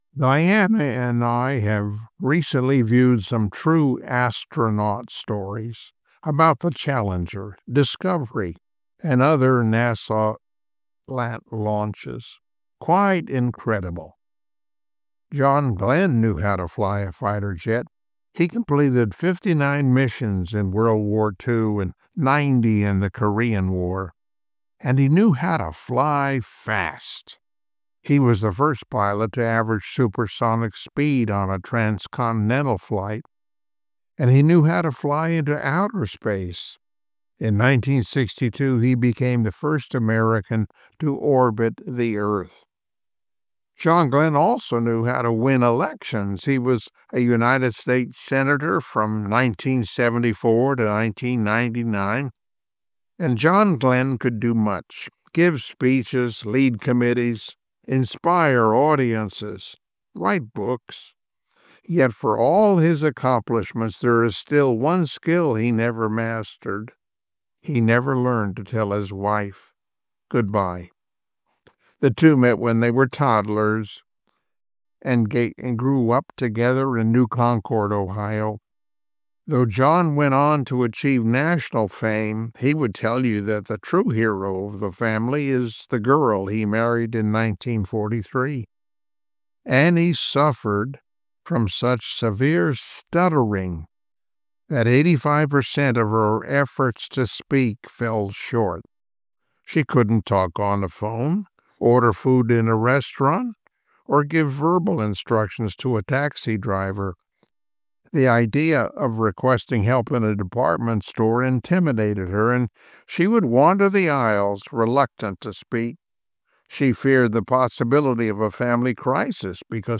Never Give Up Passage: 1 Thessalonians 4:16-17 Service Type: Sunday Morning Worship Topics